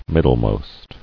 [mid·dle·most]